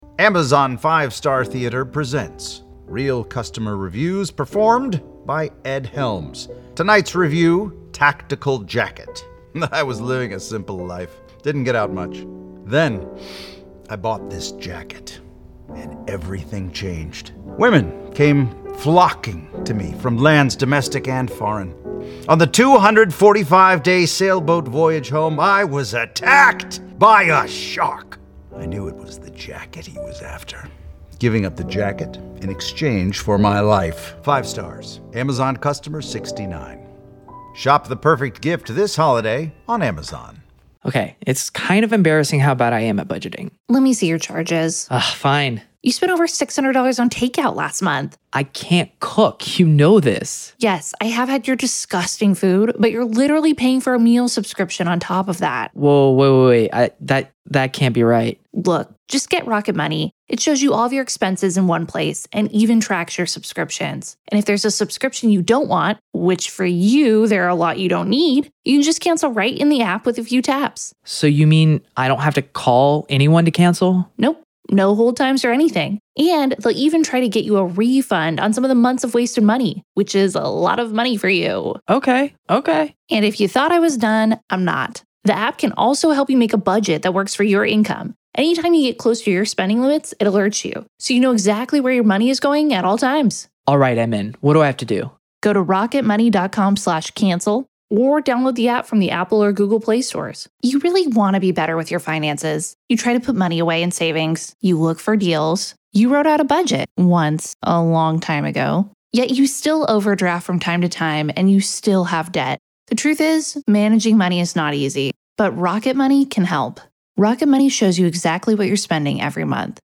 This is a conversation about behavior, not blame — and it may be the clearest breakdown of this case you’ll hear anywhere.